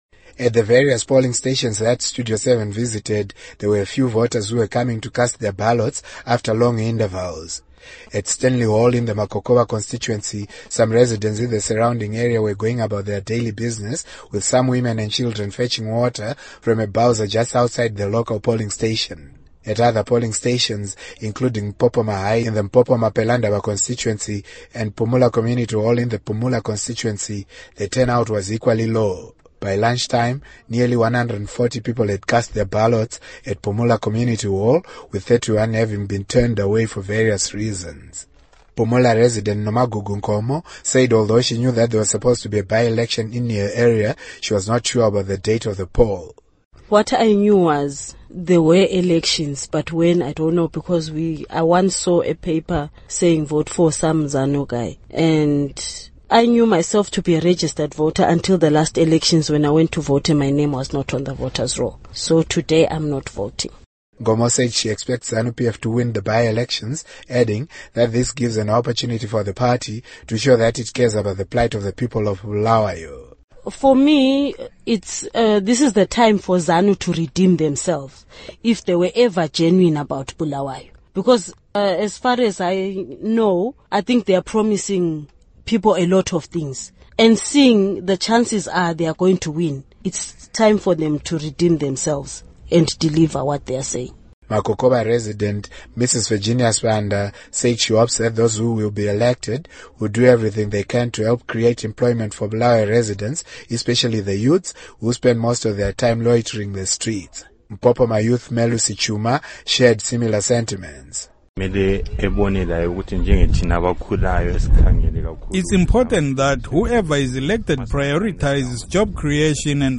Report on Parly By-Elections